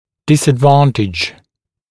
[ˌdɪsəd’vɑːntɪʤ][ˌдисэд’ва:нтидж]неудобство, невыгодное положение, недостаток